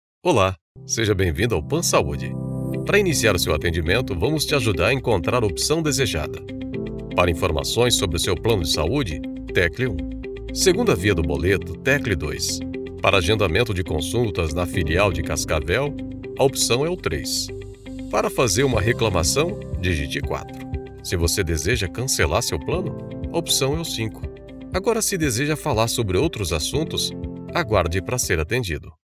Natural, Friendly, Corporate, Commercial, Versatile
Telephony